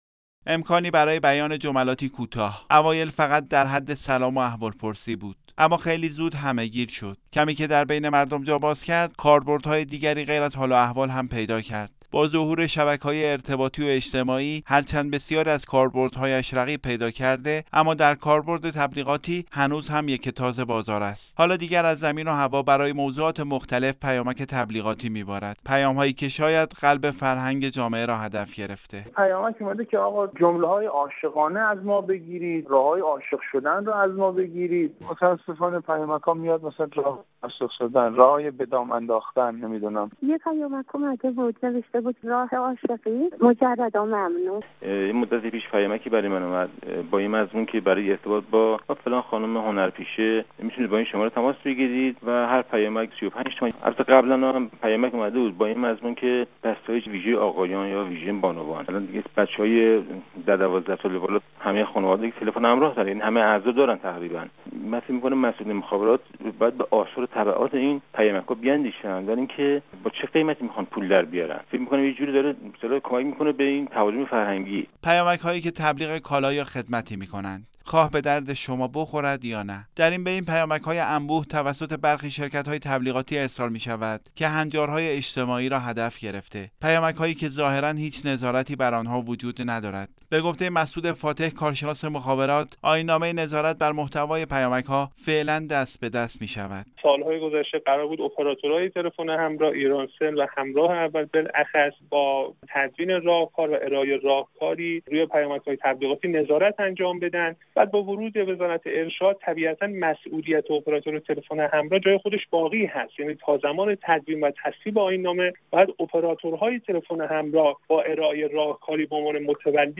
"گزارش شنیدنی" از انبوه پیامک‌های مزاحم بدون ناظر - تسنیم